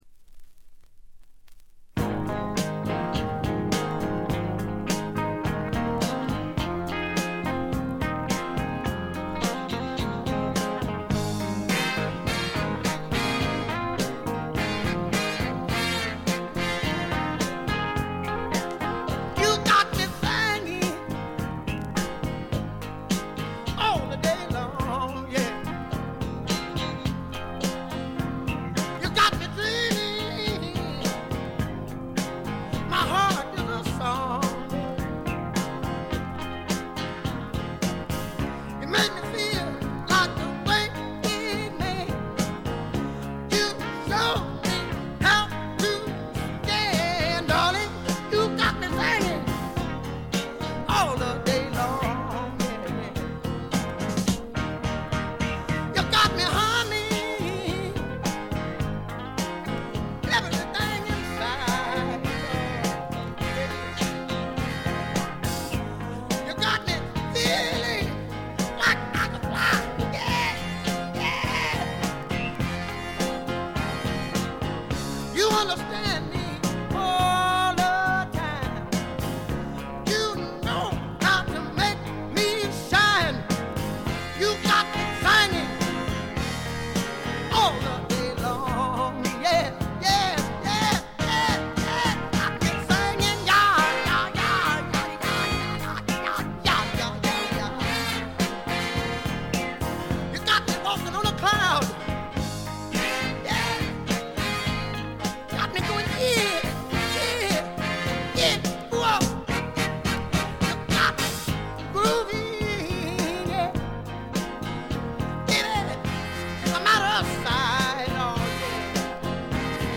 部分試聴ですが、わずかなノイズ感のみ。
予備知識がなければ100人が100人とも黒人シンガーと間違えてしまうだろうヴォーカルが、まず凄い！
試聴曲は現品からの取り込み音源です。